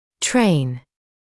[treɪn][трэйн]тренировать, обучать